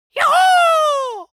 Rings.mp3